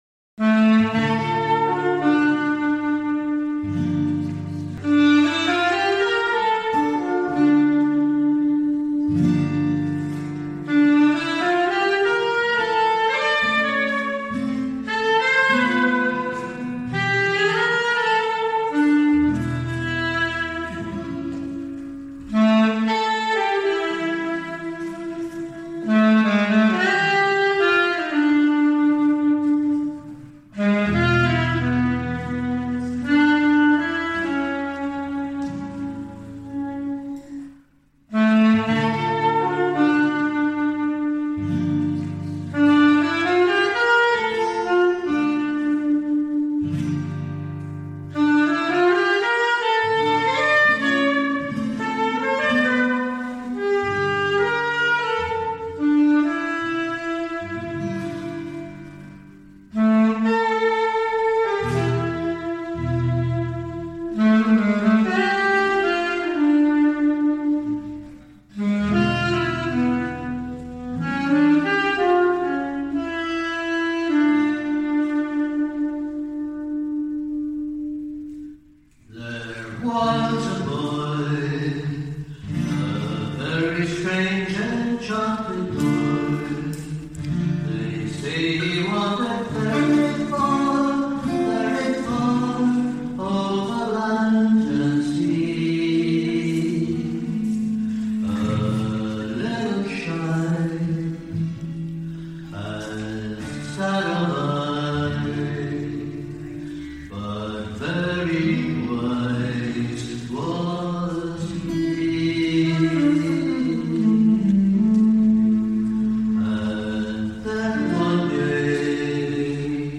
Concerts with Band of Brothers and Family